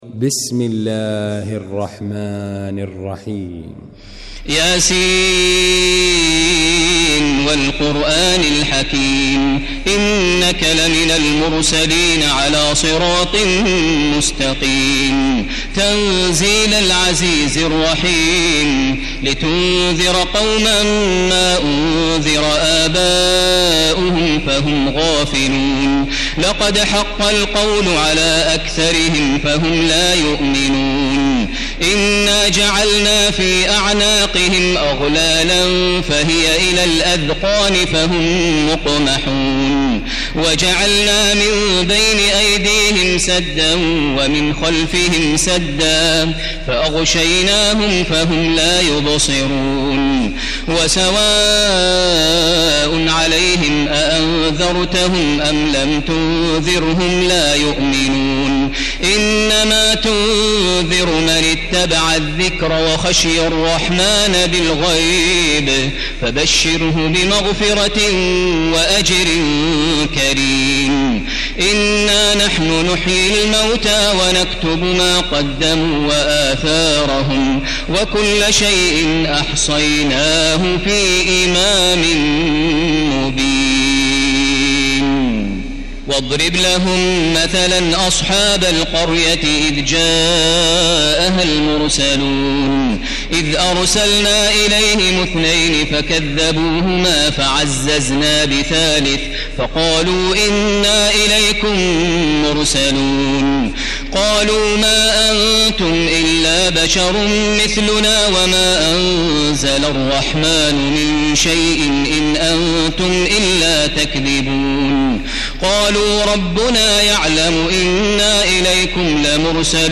المكان: المسجد الحرام الشيخ: فضيلة الشيخ ماهر المعيقلي فضيلة الشيخ ماهر المعيقلي يس